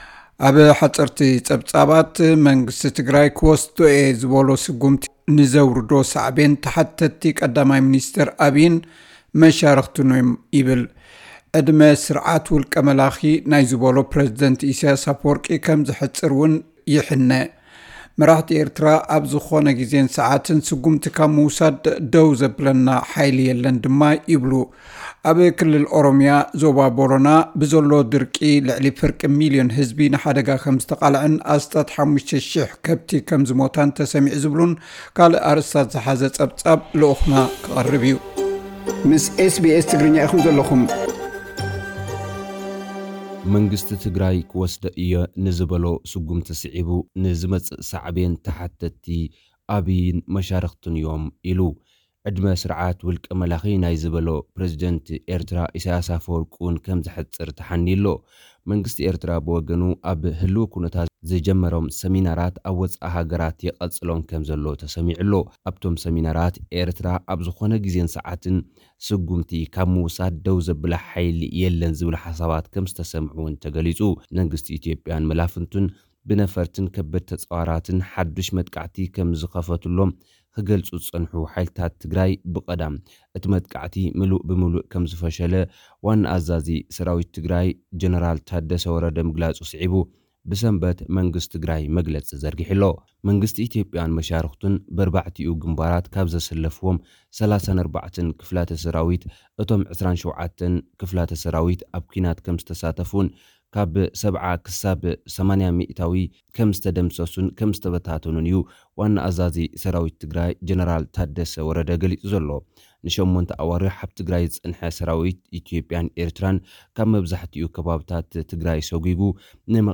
ሓጸርቲ ጸብጻባት፥